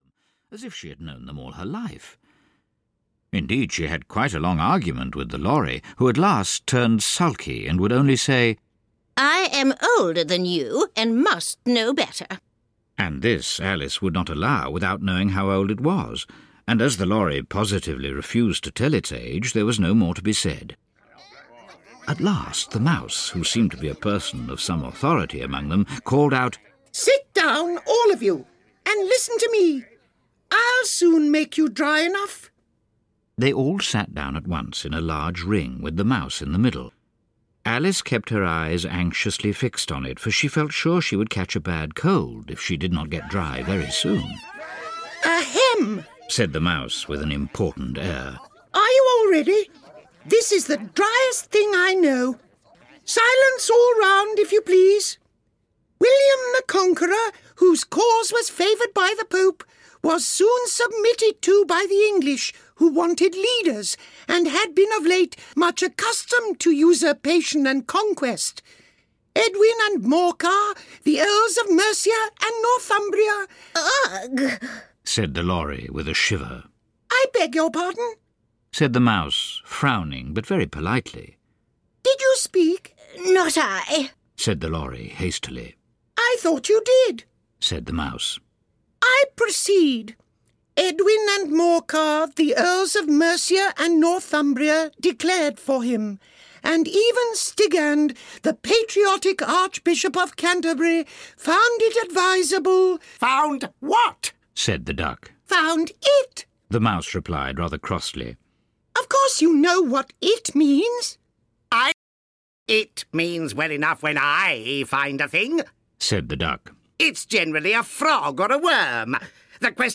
Ukázka z knihy
Alice’s adventures, funny, inventive and disturbing, have fascinated children and adults alike since their publication. This is a new unabridged recording using many voices to take the parts of The White Rabbit, the Mad Hatter, the Queen of Hearts and the Cheshire Cat – and, of course, Alice herself.